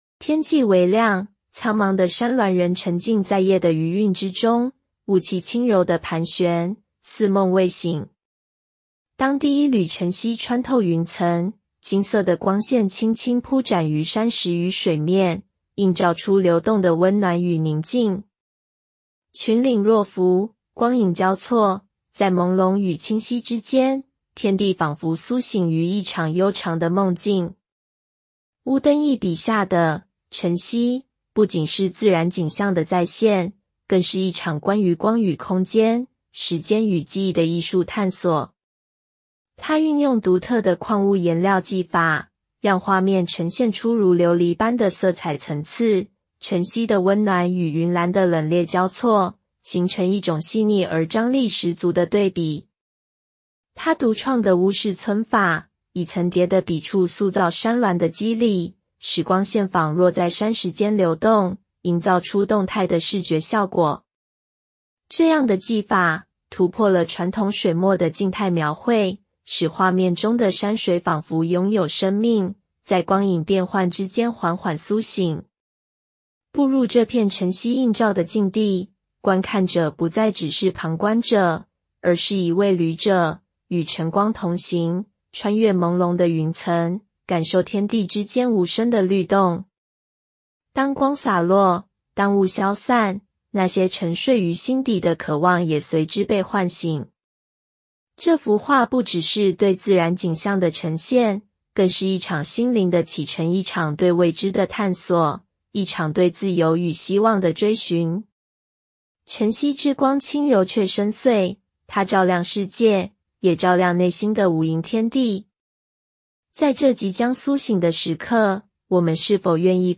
中文語音導覽